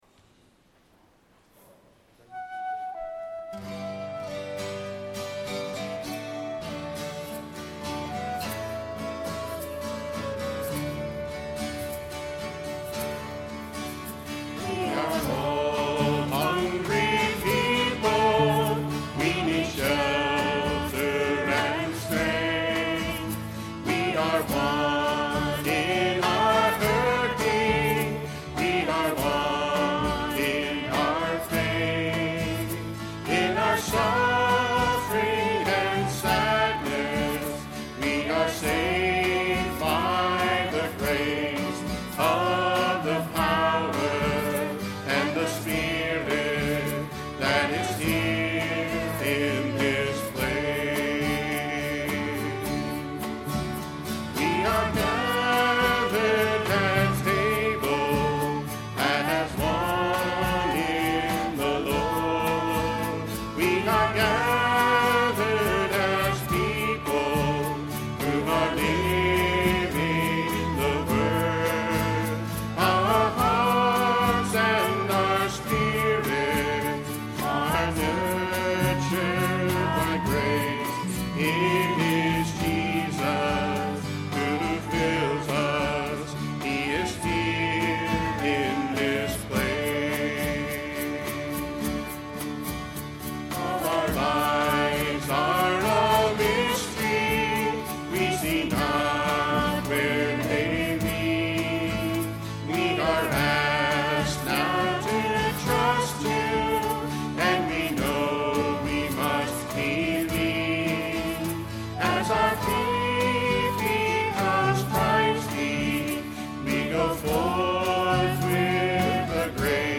10/11/09 10:30 Mass Recording of Music - BK1030
Note that all spoken parts of the Mass have been removed from this sequence.